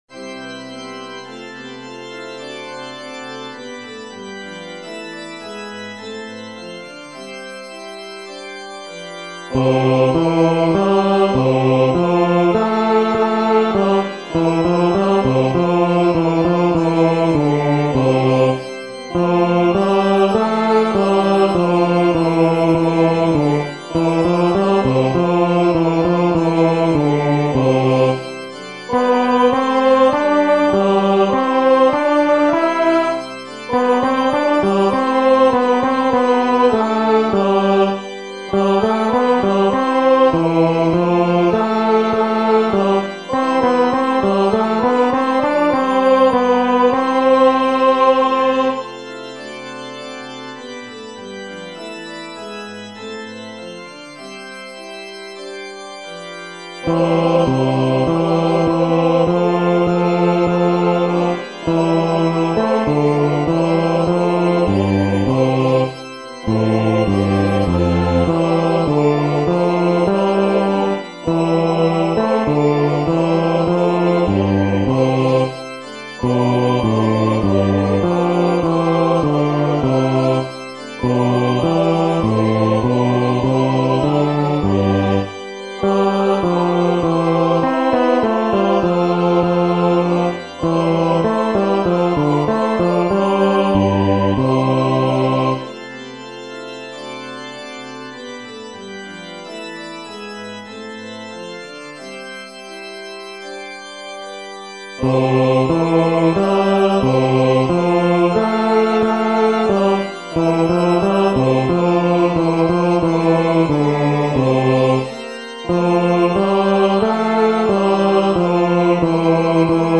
（ほぼSATB）
バス2（フレットレスバス音）